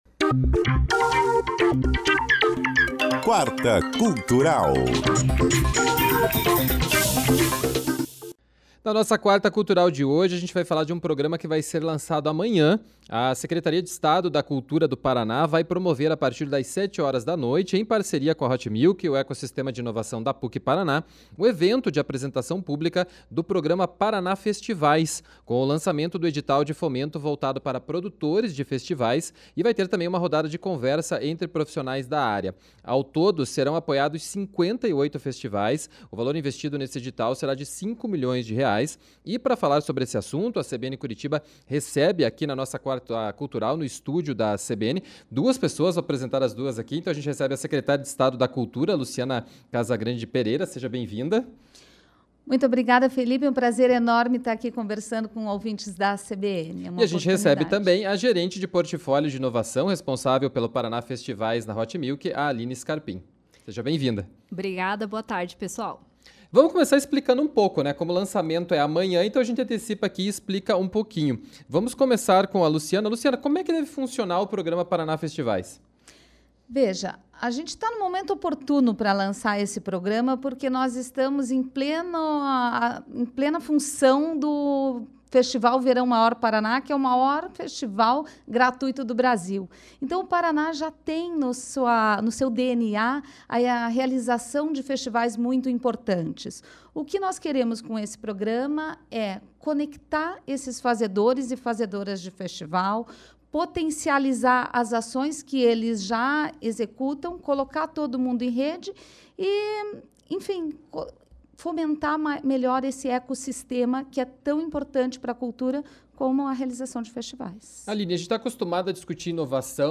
ENTREVISTA-QUARTA-CULTURAL.mp3